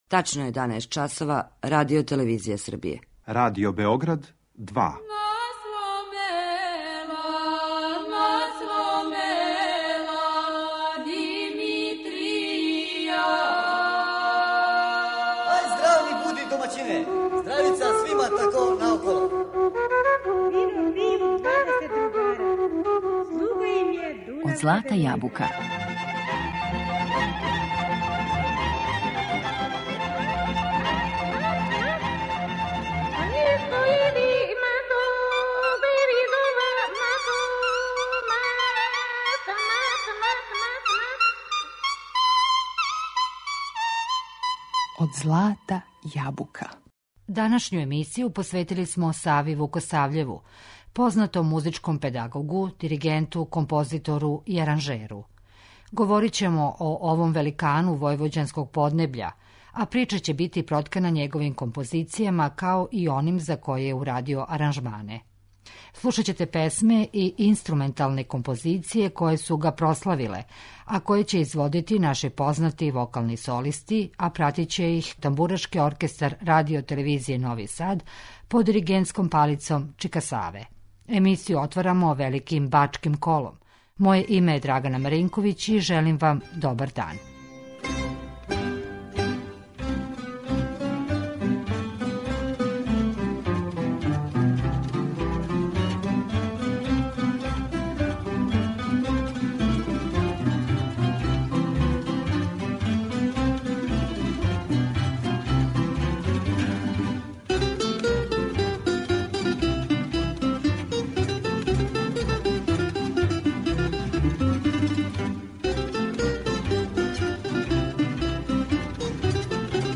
Говорићемо о овом великану војвођанског поднебља , а прича ће бити проткана његовим композицијама. Слушаћемо песме и инструменталне композиције које су га прославиле , а које ће изводити наши познати солисти уз пратњу ТО Радио Новог Сада под диригентском палицом чика Саве.